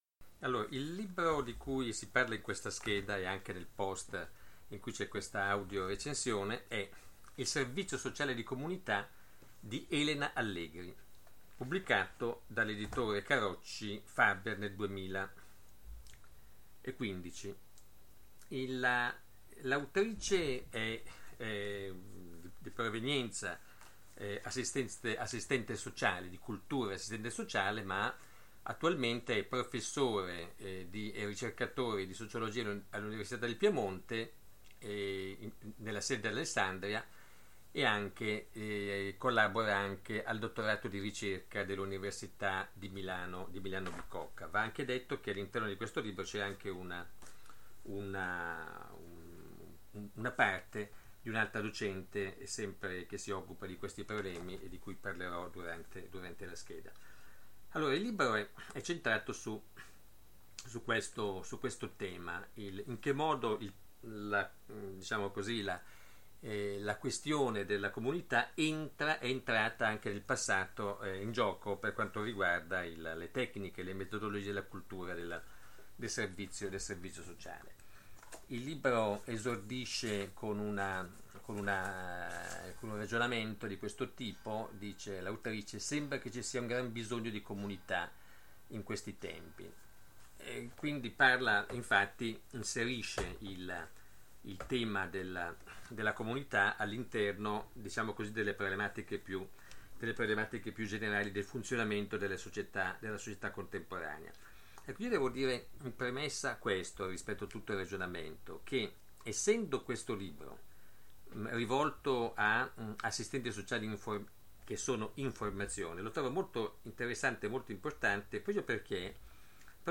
audio recensione